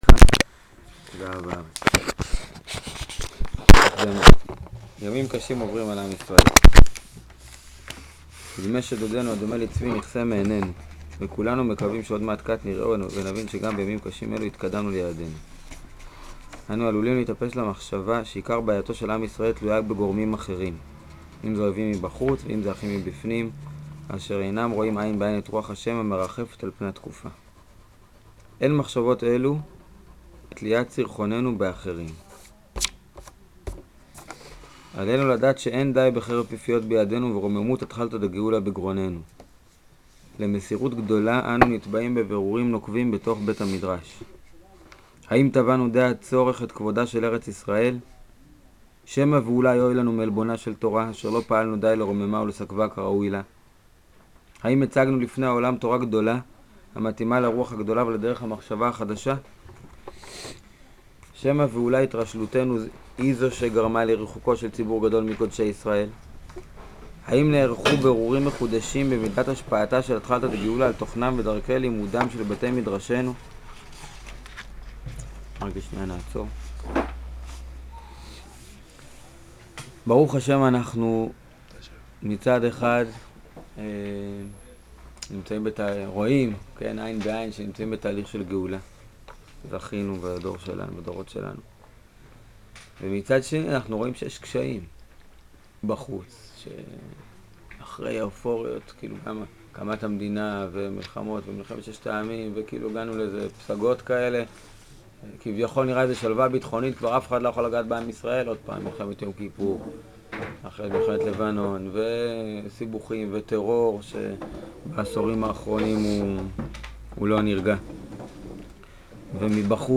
זהב הארץ - שיעור ראשון